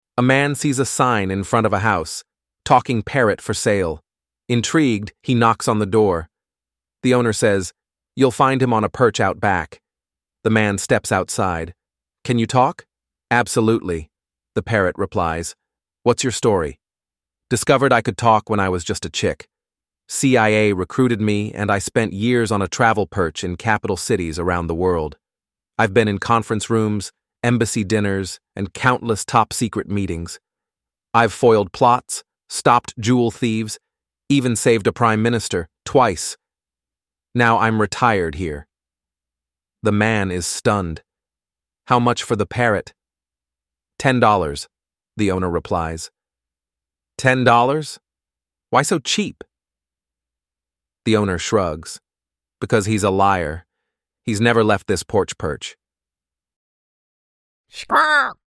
And if one talking parrot isn’t enough, click
Parrot.mp3